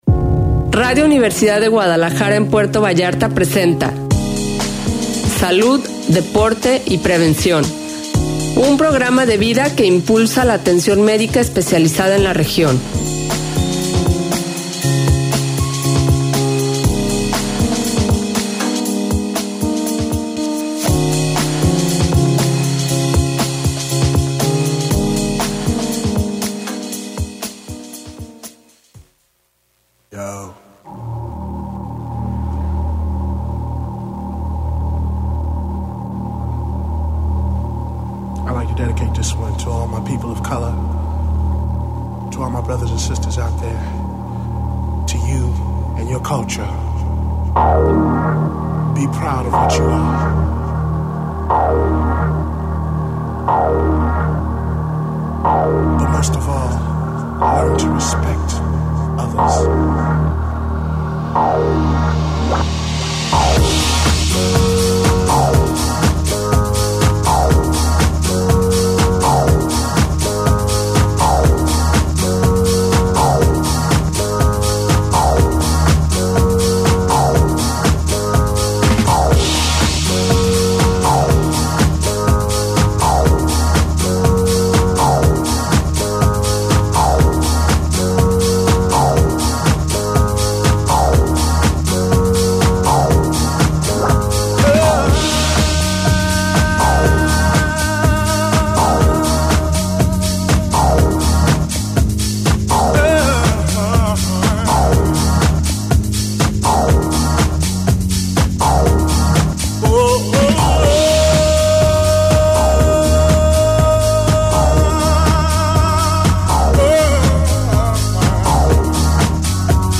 21 de Noviembre del 2024 Tema: Infecciones Respiratorias Infantiles Invitada: Pediatra